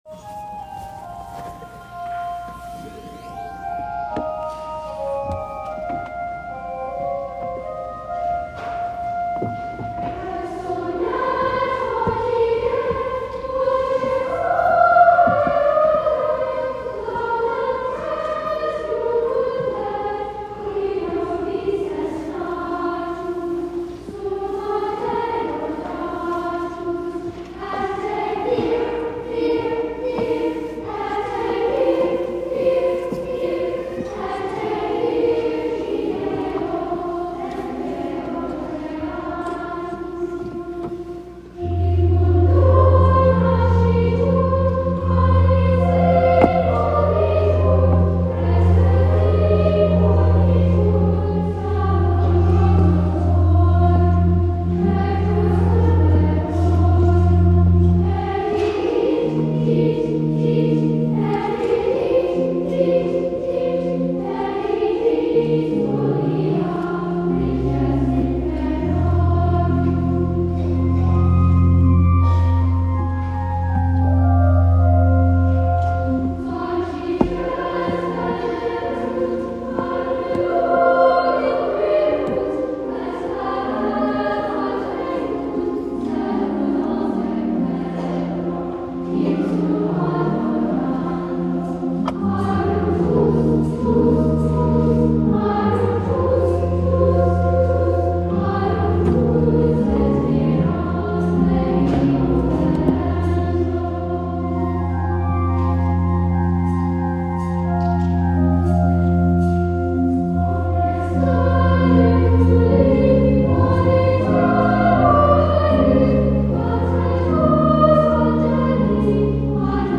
Choir Recordings — Saint Mary Church
Choristers